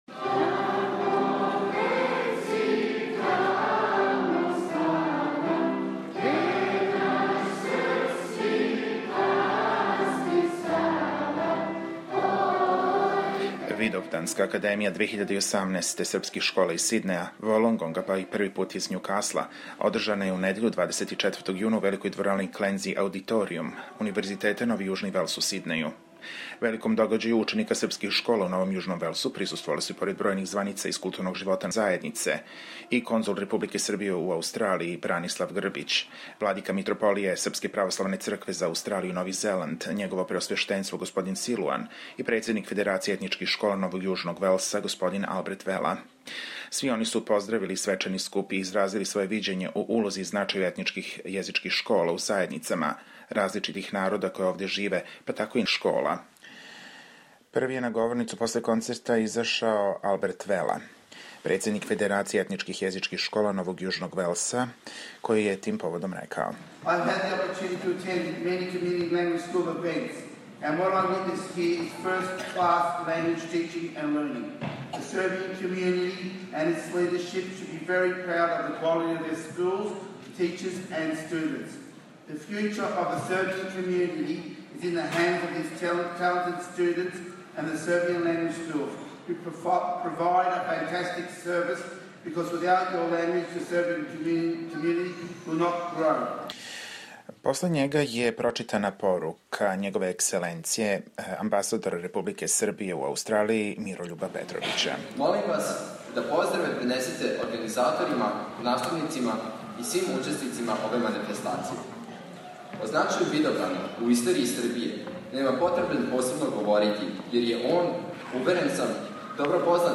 У недељу 24 јуна на Универзитету Новог Јужног Велса представљена је девета по реду, традиционална Видовданска академија – приредба ђака школа из Сиднеја и околине у којима се одржава настава српског језика.
забележио свечани део отварања Видовданске академије а затим разговарао са званицама, званичницима, ђацима и учитељима.